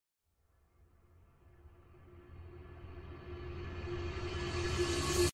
Riser Sound Effect Download: Instant Soundboard Button